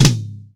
TOM     2B.wav